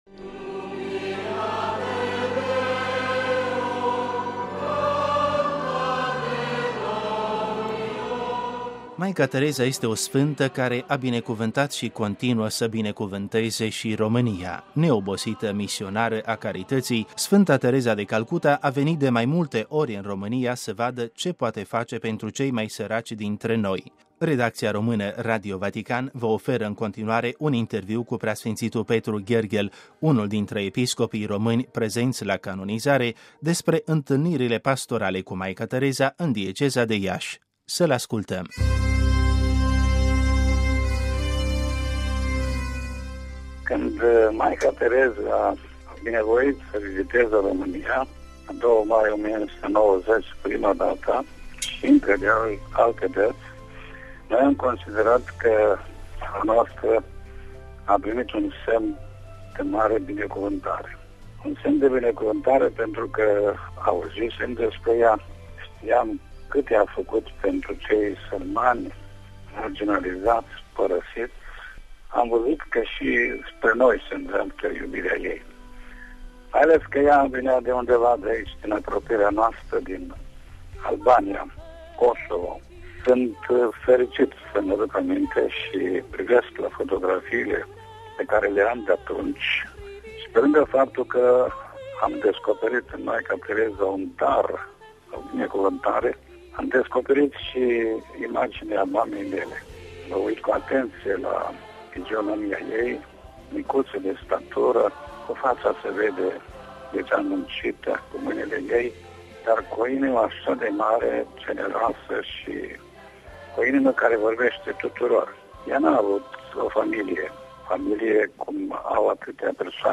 Dăruirea de mamă a Maicii Tereza este scoasă în evidență și de PS Petru Gherghel, unul din episcopii români prezenți astăzi la canonizare, într-un interviu acordat Redacției Române Radio Vatican despre întâlnirile pastorale cu Maica Tereza în dieceza de Iași.